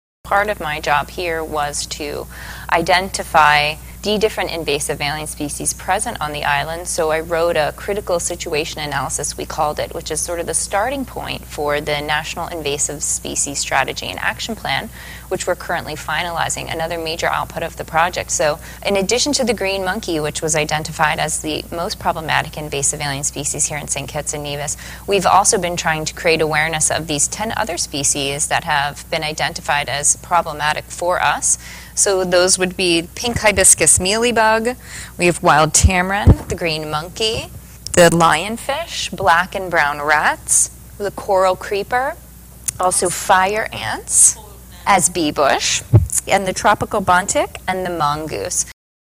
She also gave this synopsis on her role in the project: